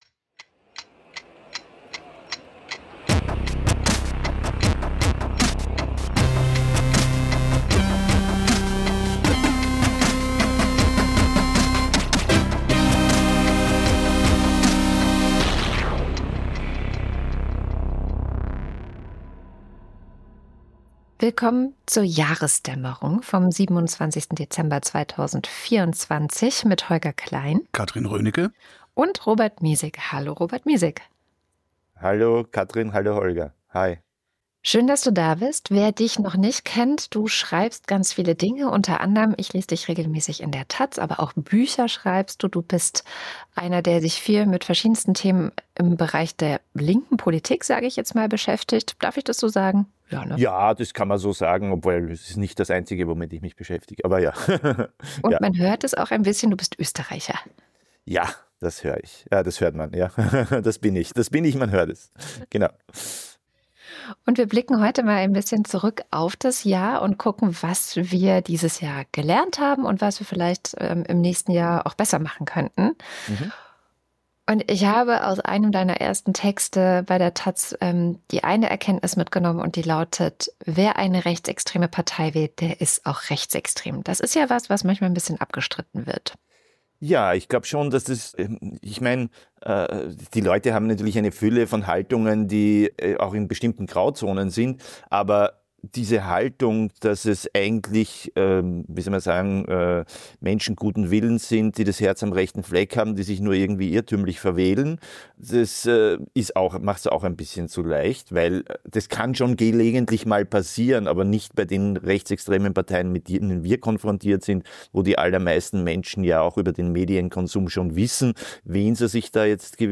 Gesprächspodcast
News Talk